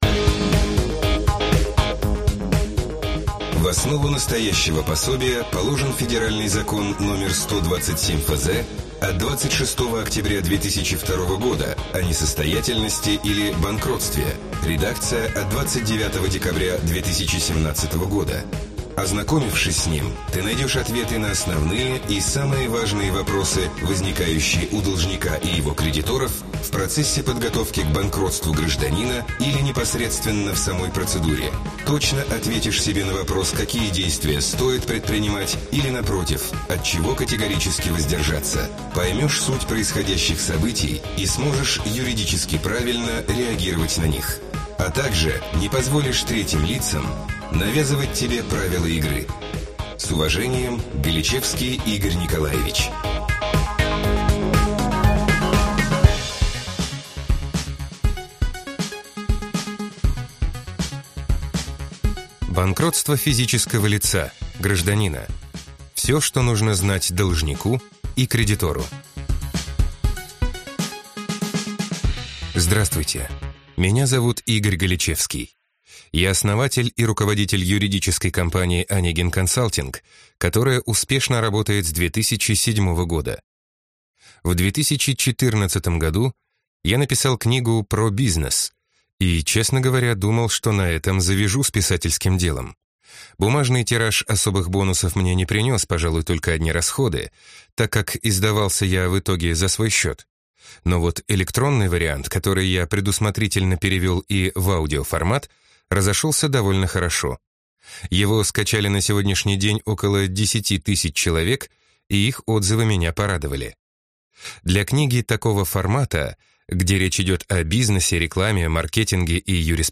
Аудиокнига Банкротство физического лица (гражданина). Все, что нужно знать должнику и кредитору | Библиотека аудиокниг